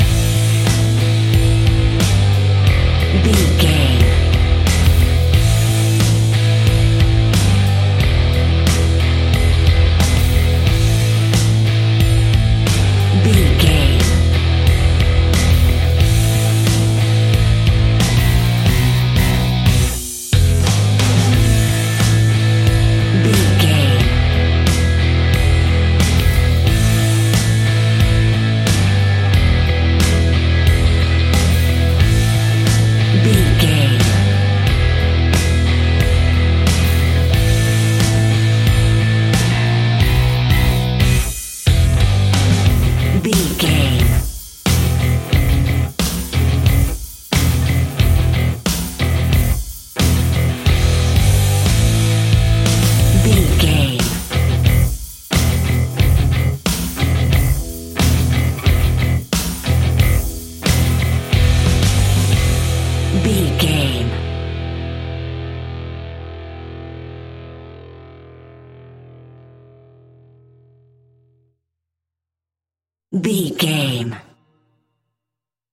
Aeolian/Minor
Slow
hard rock
heavy metal
blues rock
instrumentals
Rock Bass
Rock Drums
heavy drums
distorted guitars
hammond organ